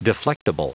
Prononciation du mot deflectable en anglais (fichier audio)
Prononciation du mot : deflectable